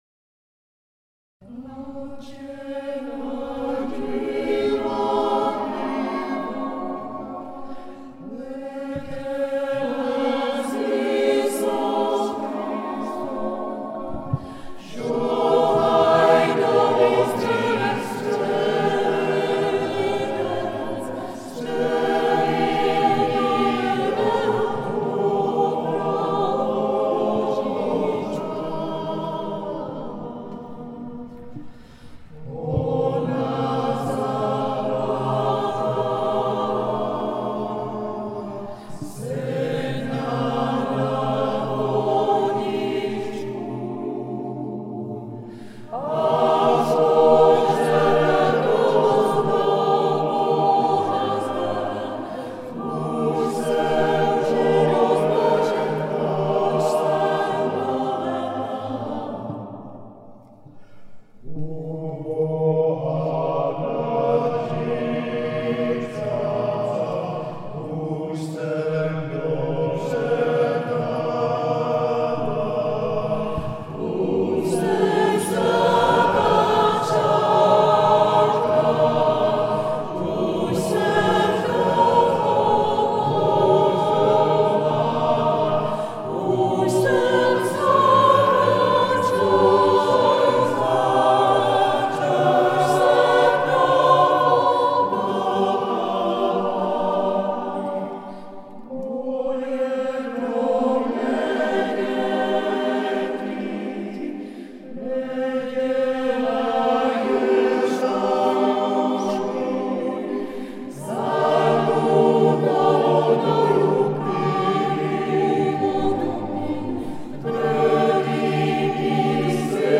Zvuková ukázka z vystoupení v aldenském kostele Panny Marie